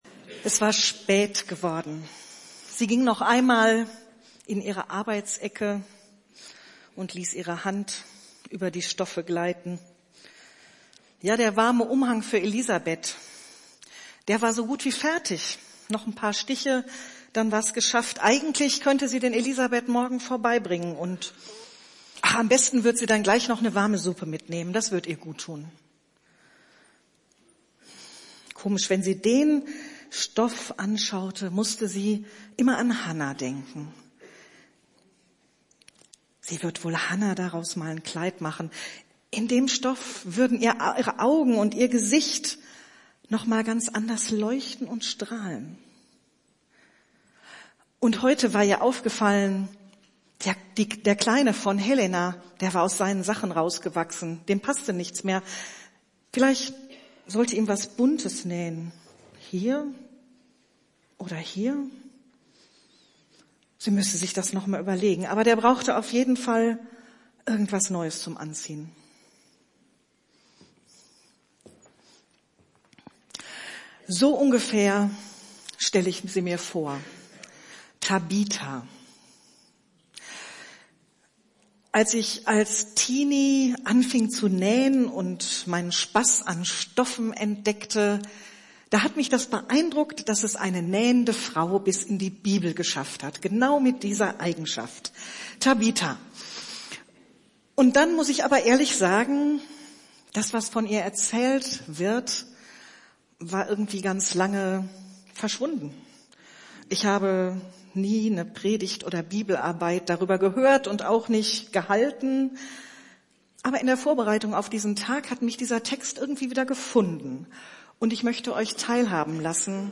Predigttext: Apostelgeschichte 9,36-42